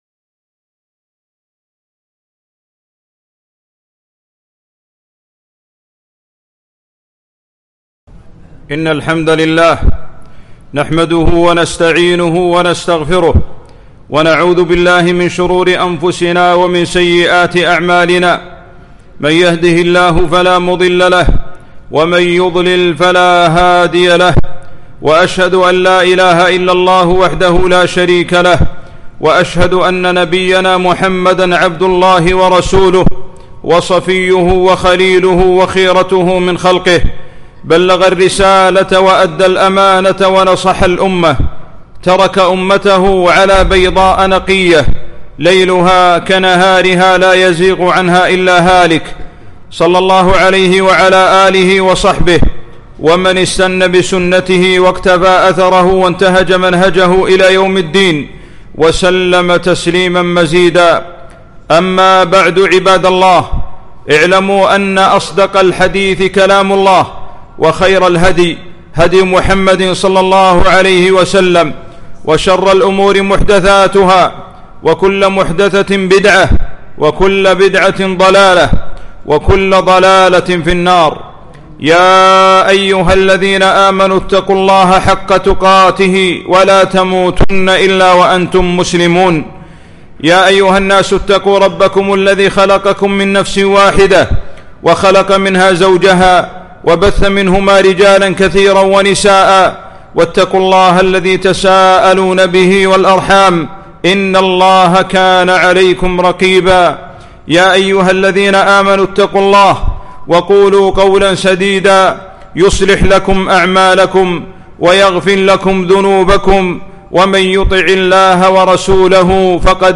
معرفة حق الله على عباده - خطبة